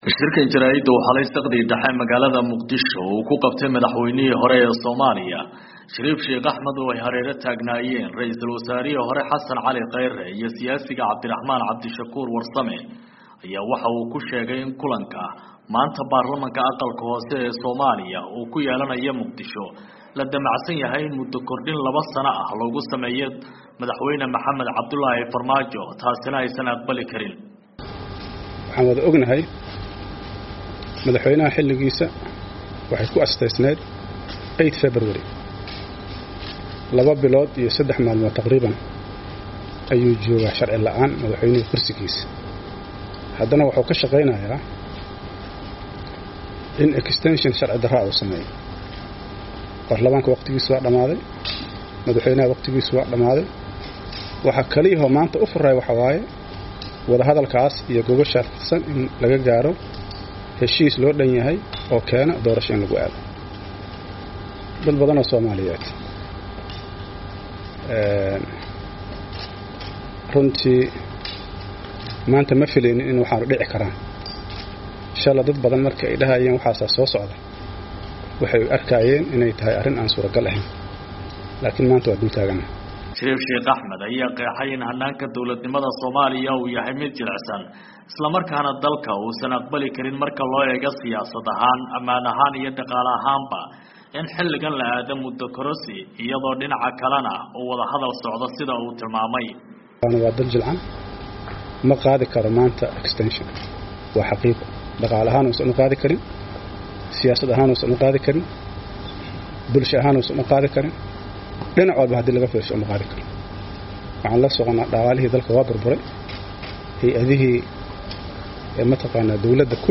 Warbixinta shirka jaraa'id ee Sheekh Shariif